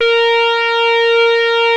OSCAR A#4 5.wav